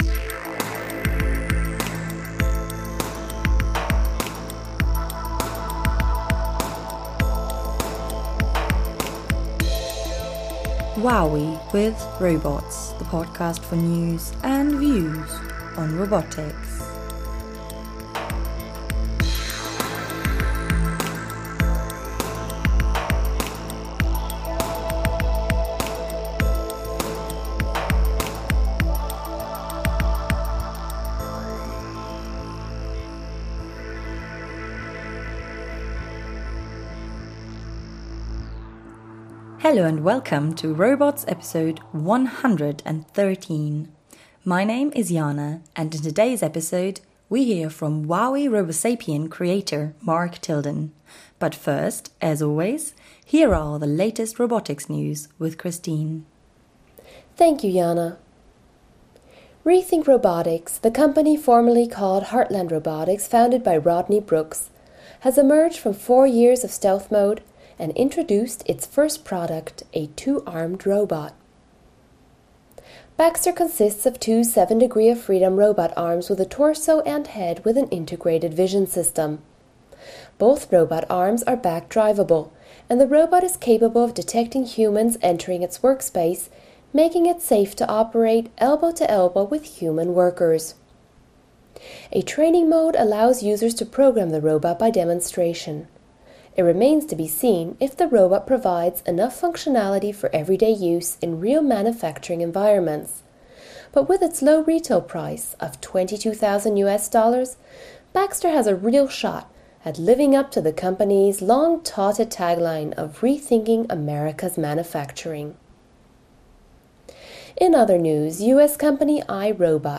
In today’s episode we speak with Mark Tilden, about the history before WowWee‘s RoboSapien and FemiSapien and about his belief that bottom up BEAM robotics (which stands for Biology, Electronics, Aesthetics, and Mechanics) is essential in creating low cost, competent, robust and flexible robots.